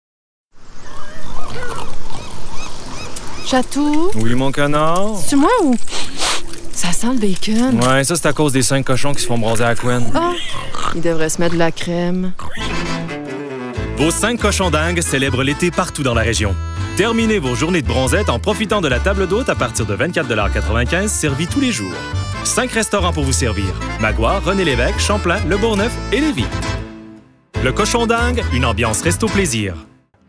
Timbre Médium - Grave
Cochon Dingue - Sympathique - Personnage typé (bon gars) - Québécois familier et soutenu /
Pub + Annonceur 2018 00:30 1 Mo